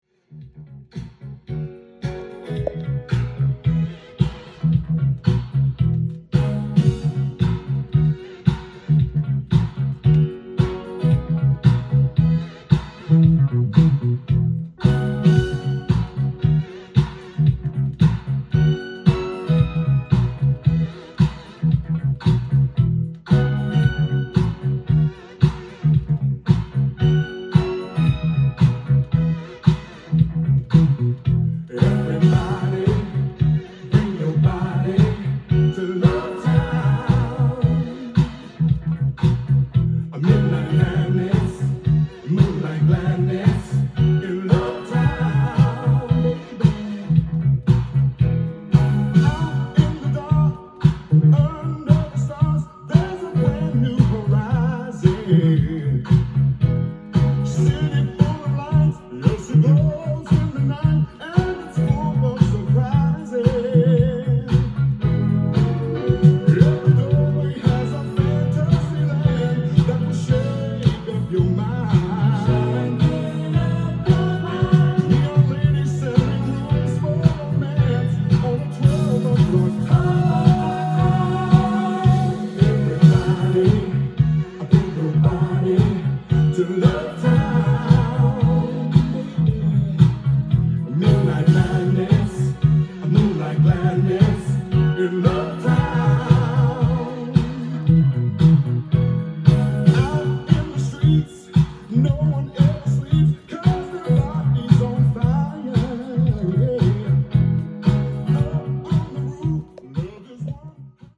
ジャンル：SOUL
店頭で録音した音源の為、多少の外部音や音質の悪さはございますが、サンプルとしてご視聴ください。